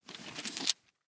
Звуки сумки, ранца
Звук: взяли ранец со стола